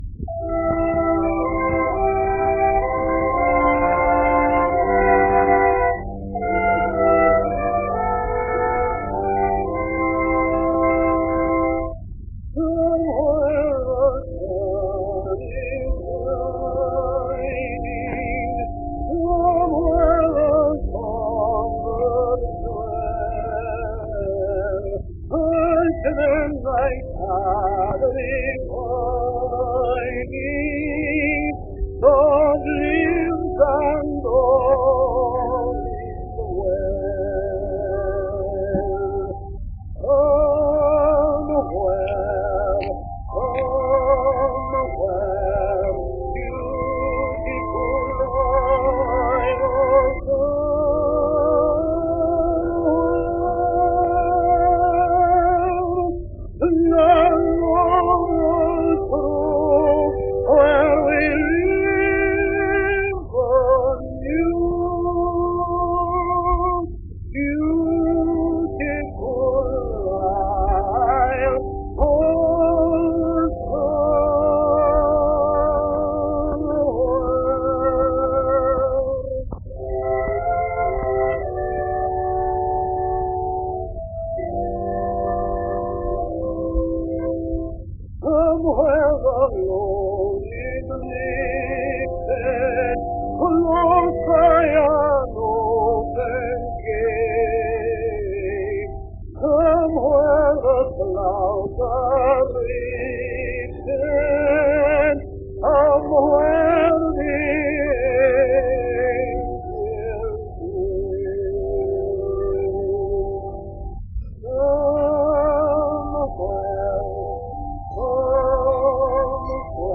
A pair of 1914 recordings by the famous tenor singer.